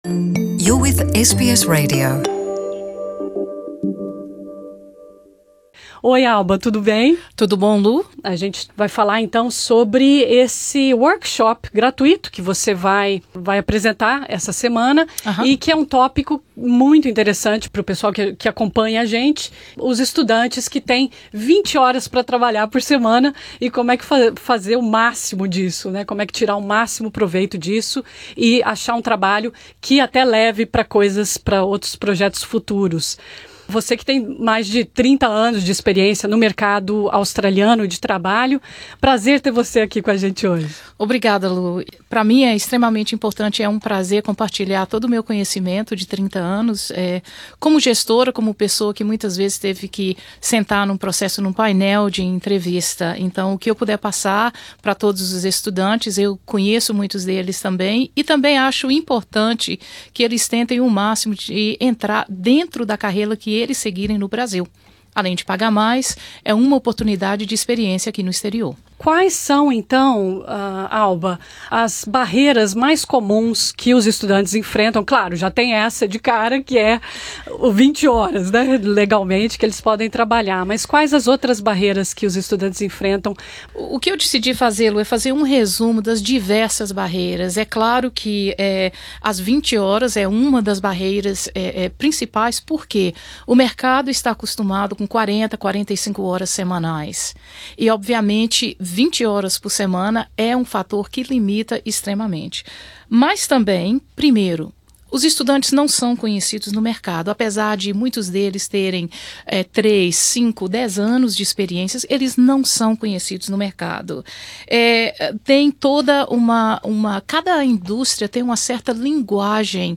Nessa entrevista ela ressalta a importância de cultivar e 'vender' uma boa imagem pessoal, melhorar a comunicação e o nível de inglês, voluntariar e se reinventar na Austrália.